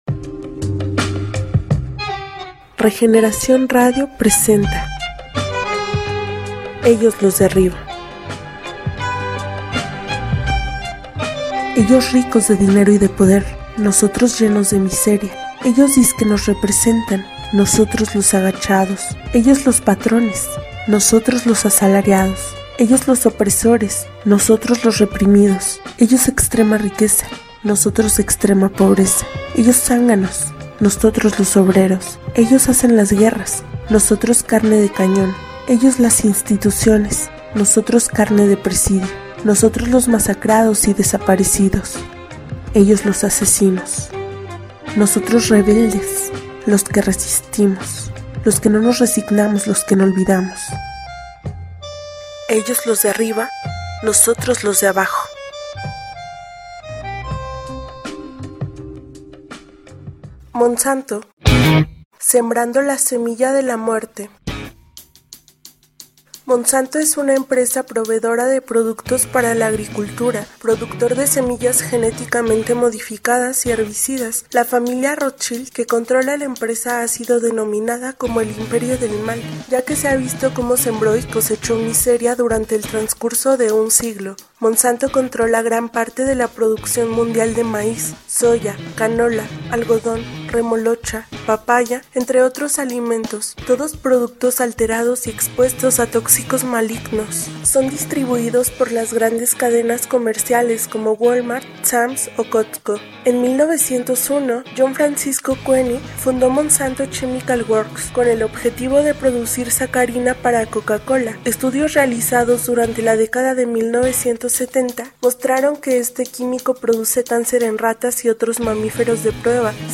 Cápsula respecto a la historia de monsanto